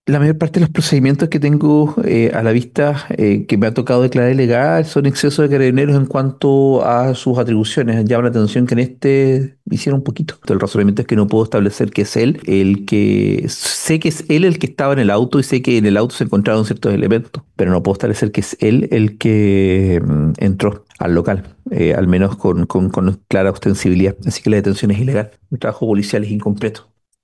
Los dos atrapados terminaron detenidos sindicados como autores de una presunta sustracción de pan, paltas y congelados desde un minimarket, además de amenazas, situación que en tribunales no logró ser acreditada, según quedó registrado en la audiencia en voz del juez Cristian Alfonso, quien cuestionó el procedimiento policial.